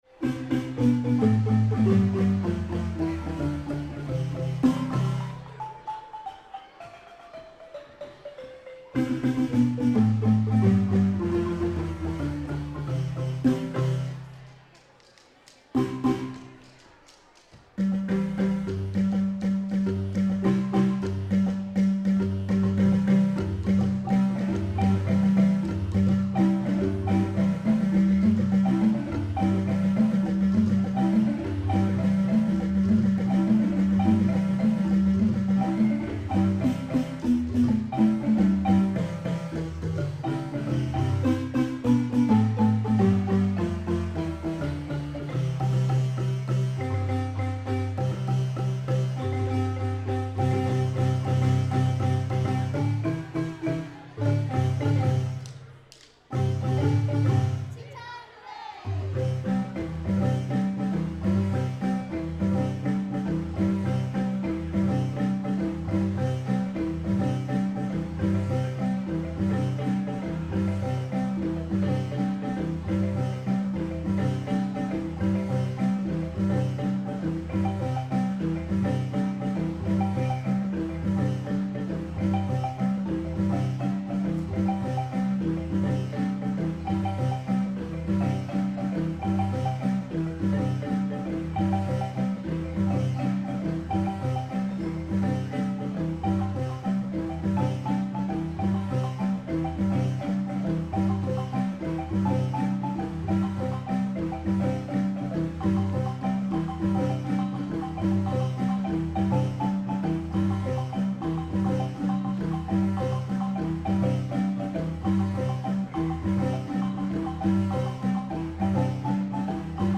The Njuzu Marimbas opened the Mayor’s Awards for the Arts on Friday evening at the Juneau Arts and Culture Center.
Standing in for the mayor, Deputy Mayor Jerry Nankervis presented awards to:
Live music will be provided by the Njuzu Marimbas.